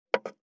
572魚切る,肉切りナイフ,
効果音厨房/台所/レストラン/kitchen食器食材